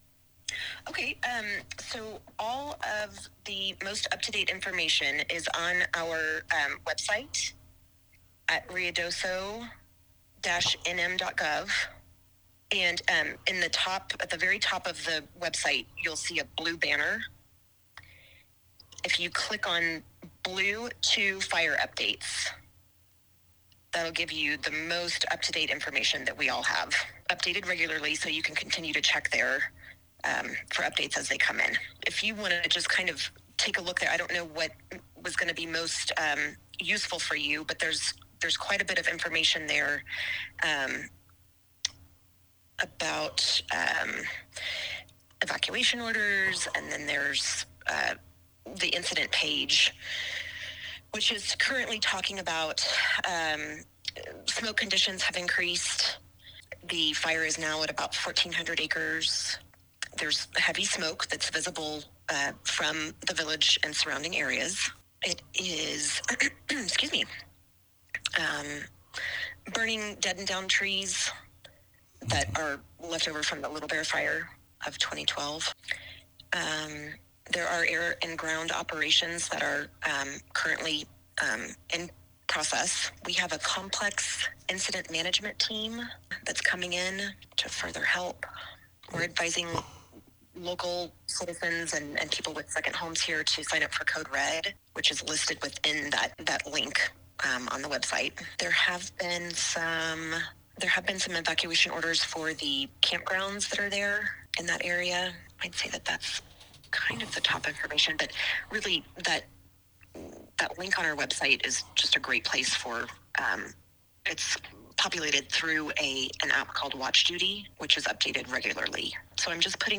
SPECIAL NEWS REPORT ON BLUE 2 FIRE WITHIN WHITE MOUNTAINS WILDERNESS.